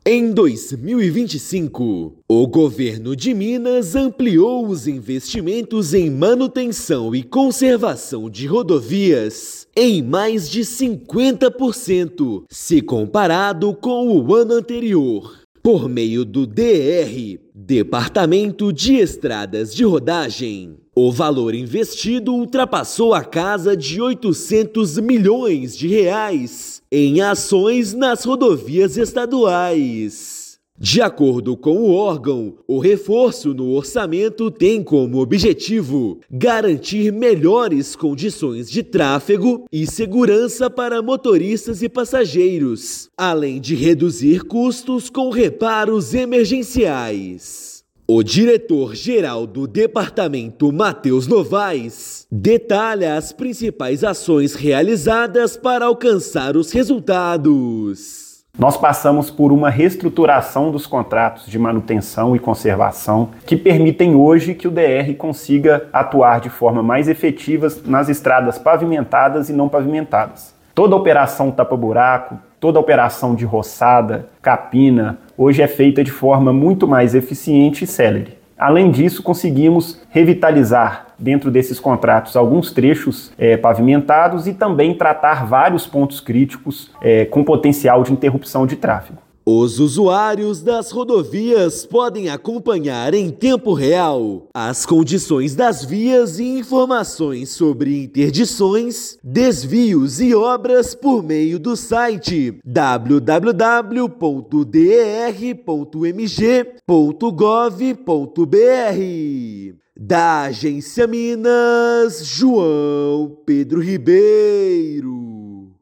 Valor representa aumento de 50% em relação ao aplicado em 2024 e reforça compromisso do Estado com a melhoria das estradas mineiras. Ouça matéria de rádio.